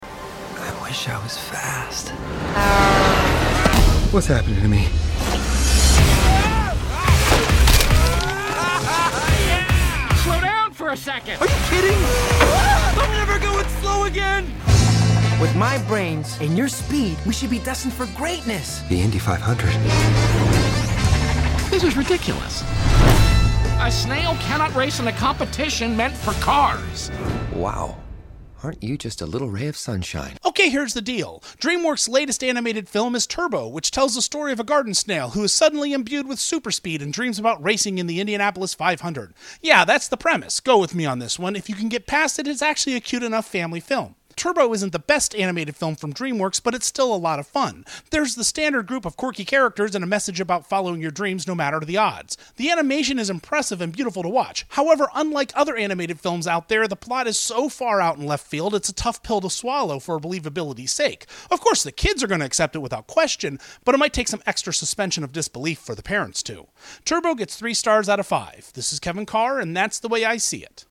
‘Turbo’ Movie Review